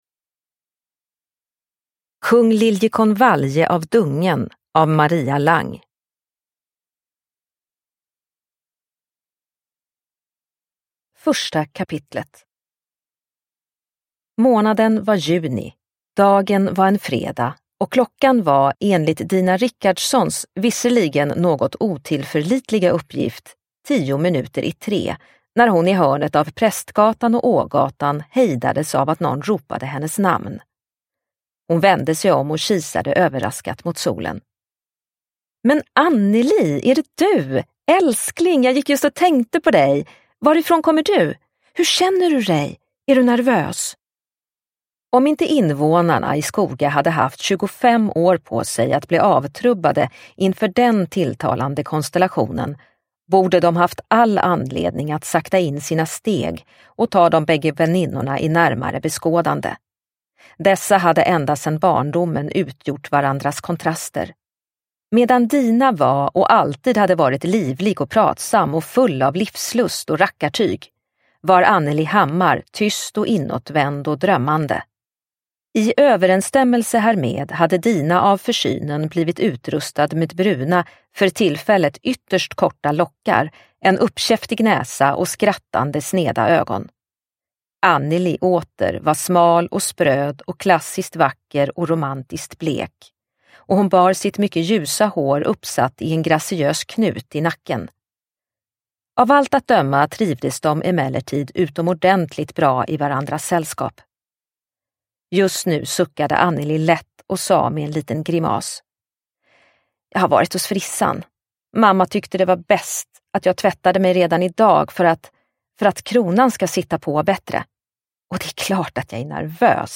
Kung Liljekonvalje av dungen – Ljudbok – Laddas ner